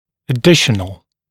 [ə’dɪʃənl][э’дишэнл]дополнительный, добавочный